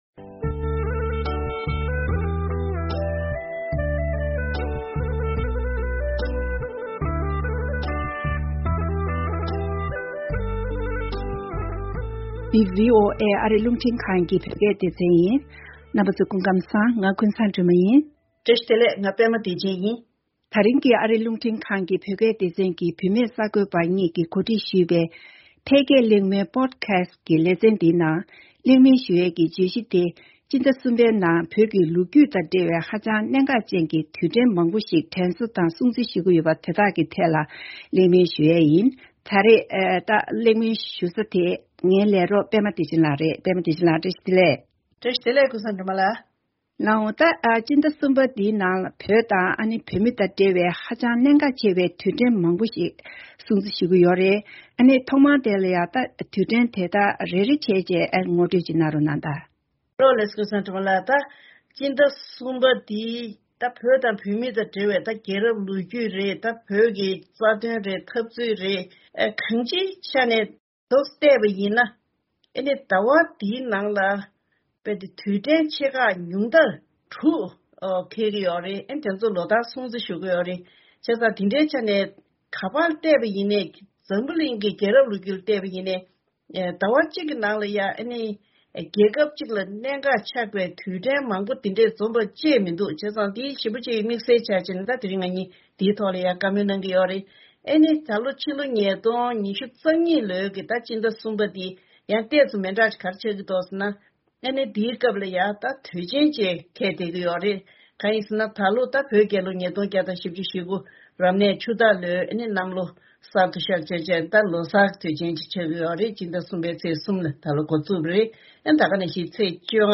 ༄༅། དེ་རིང་གི་ང་ཚོའི་ཨ་རིའི་རླུང་འཕྲིན་ཁང་གི་བུད་མེད་གསར་འགོད་པ་གཉིས་ཀྱིས་གོ་སྒྲིག་ཞུས་པའི་ཕལ་སྐད་གླེང་མོལ་ཕོ་ཌི་ཁ་སི་ལེ་ཚན་ལ་ཕྱི་ཟླ་གསུམ་པའི་ནང་དུ་བོད་ཀྱི་ལོ་རྒྱུས་དང་འབྲེལ་བའི་ཧ་ཅང་གནད་འགག་ཅན་གྱི་དུས་དྲན་མང་པོ་ཞིག་སྲུང་བརྩི་ཞུ་བཞིན་པ་ཁག་ལ་གླེང་མོལ་ཞུས་པ་དེ་གསན་རོགས་གནང་།།